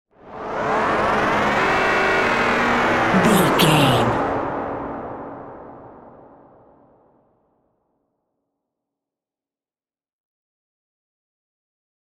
Atonal
scary
tension
ominous
dark
suspense
eerie
synth
keyboards
ambience
pads